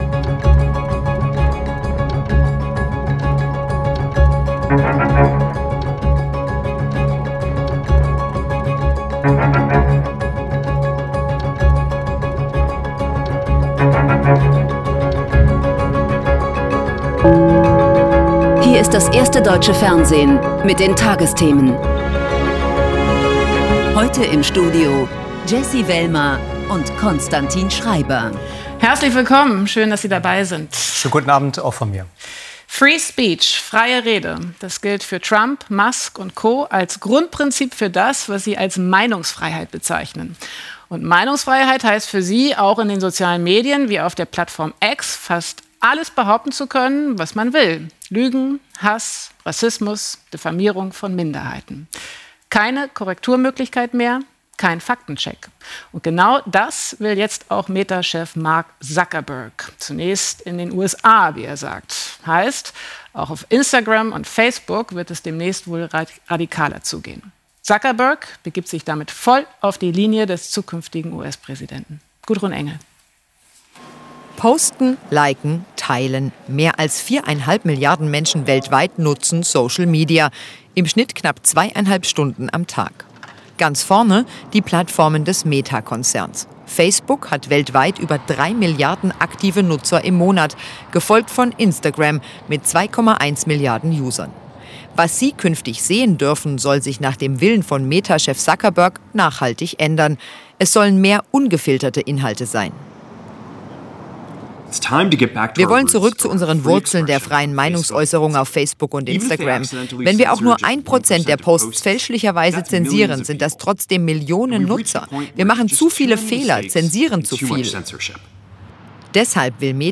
Die tagesthemen als Audio-Podcast.